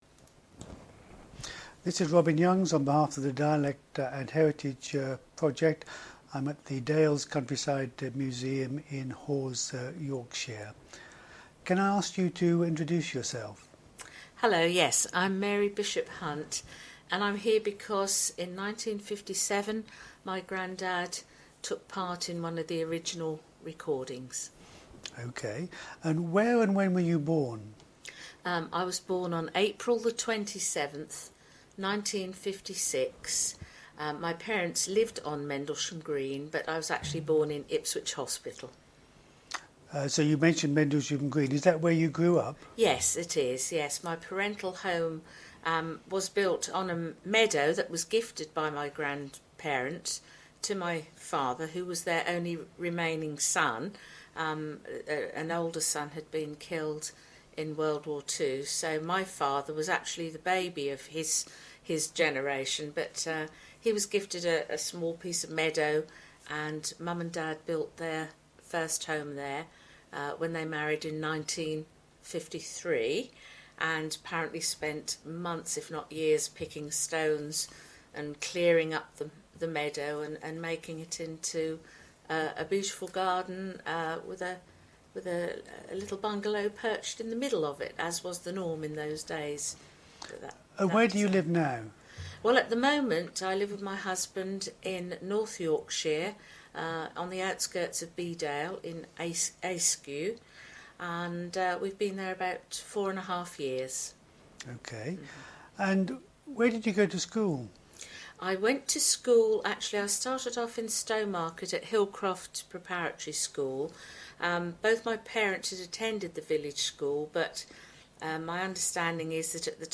Digital recording of oral history interview